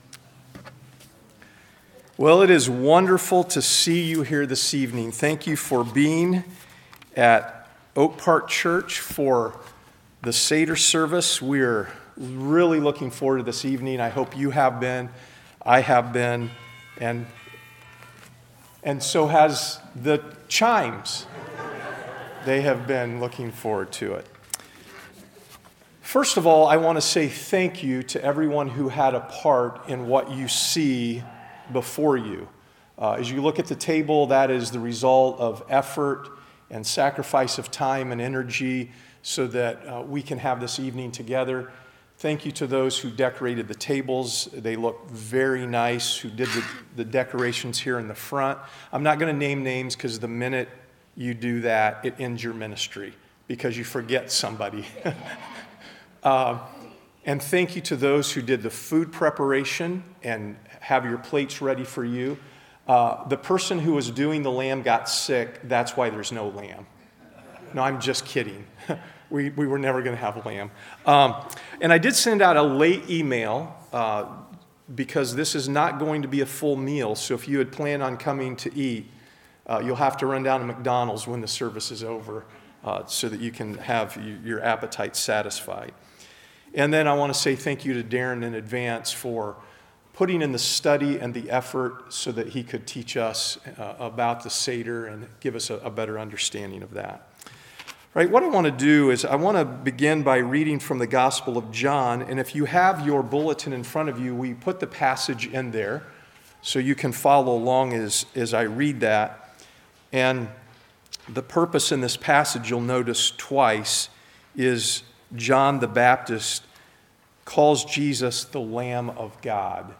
Series: Good Friday Service